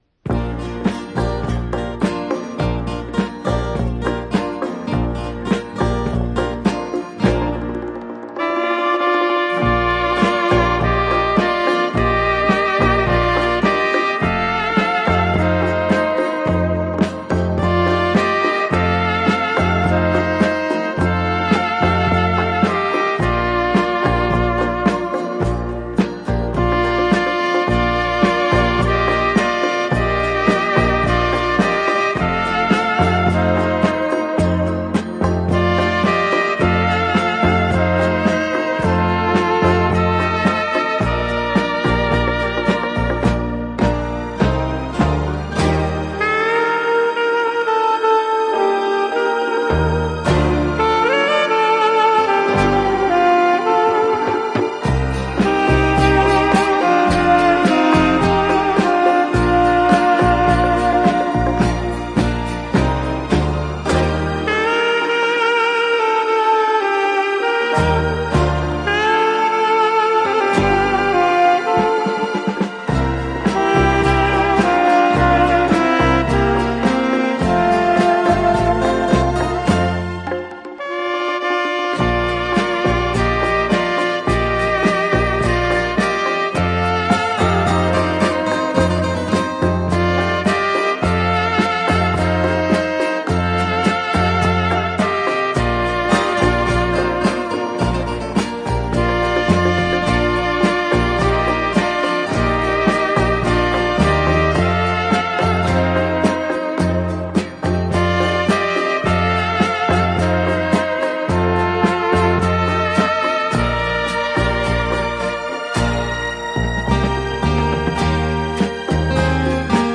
Жанр: Easy Listening, Sax